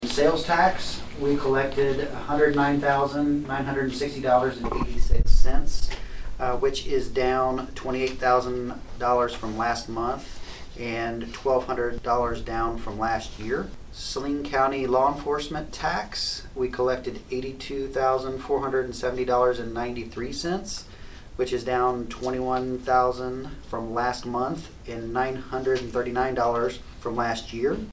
Saline County Treasurer Jared Brewer gave his monthly report on the sales-tax money the county received this month at the meeting of the county commission on Thursday, July 8.